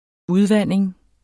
Udtale [ -ˌvanˀeŋ ]